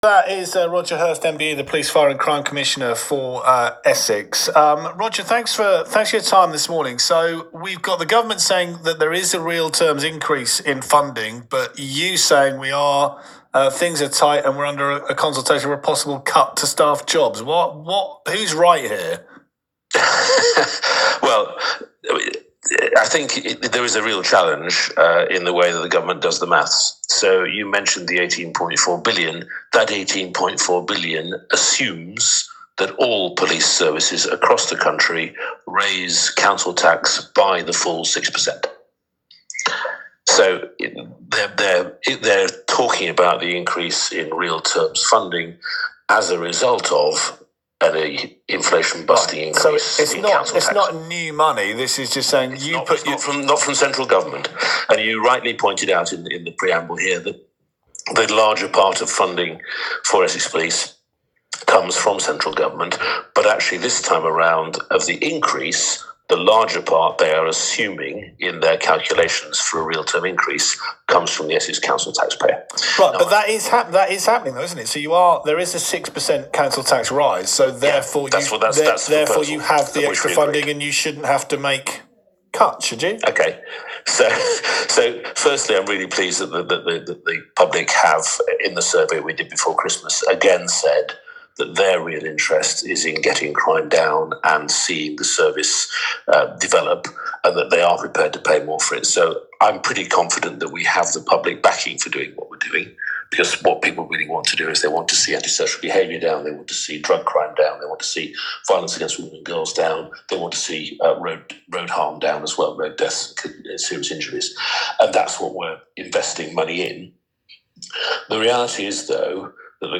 Roger Hirst, Police, Fire and Crime Commissioner speaking to BBC Essex.
Roger Hirst MBE, Police, Fire and Crime Commissioner for Essex, spoke on BBC Essex on Wednesday 4th March.